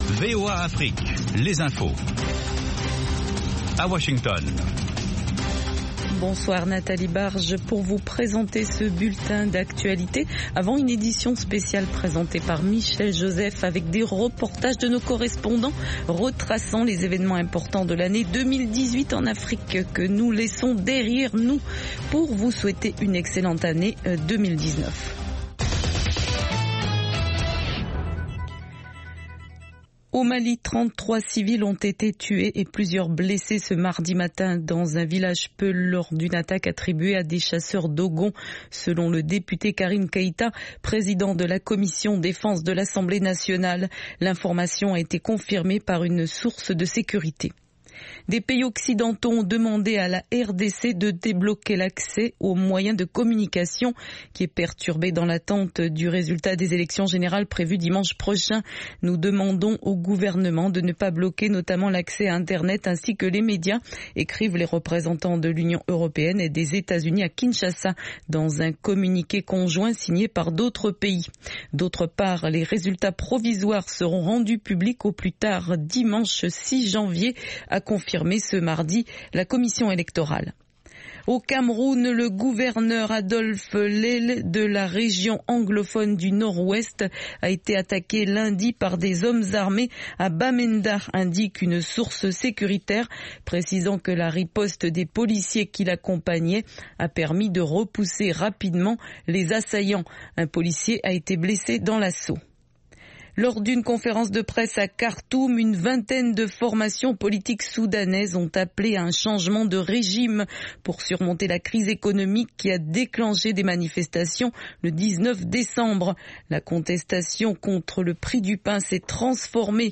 Le programme phare du service francophone dure 30 minutes sur les informations de dernières minutes, des reportages de nos correspondants, des interviews et analyses sur la politique, l’économie, les phénomènes de société et sur la société civile